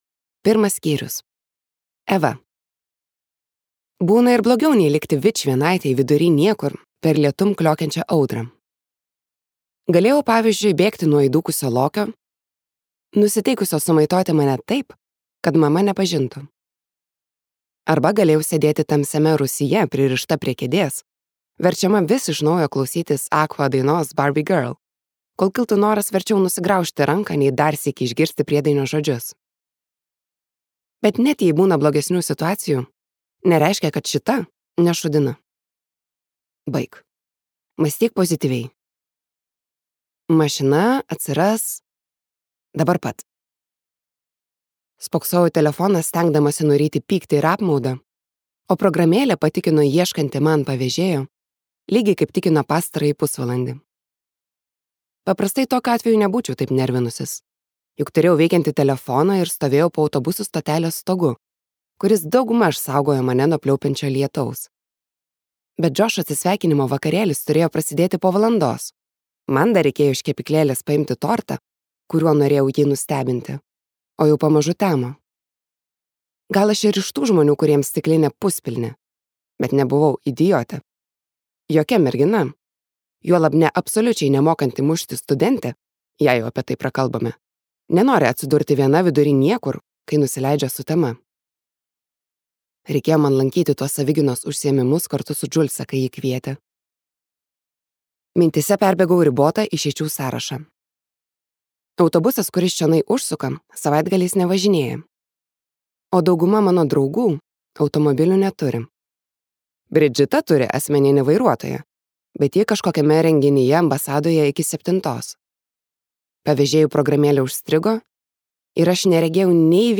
Sukta meilė | Audioknygos | baltos lankos